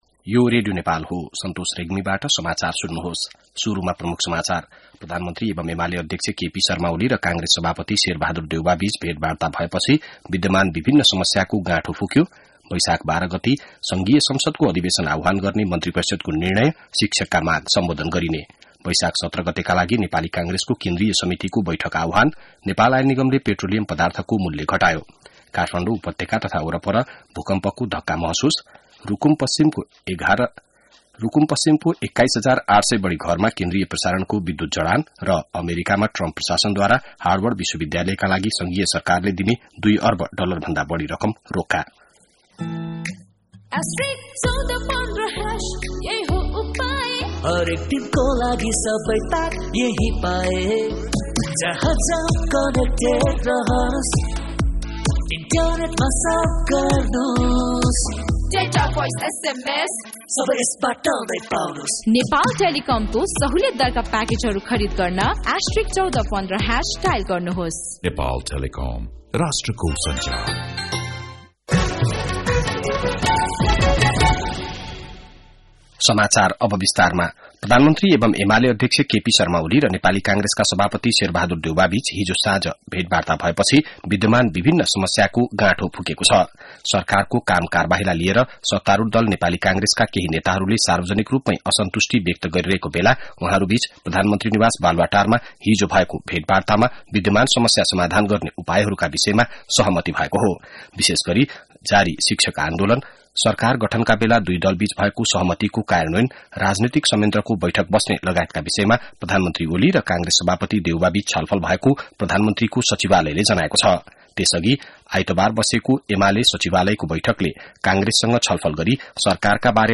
बिहान ७ बजेको नेपाली समाचार : ३ वैशाख , २०८२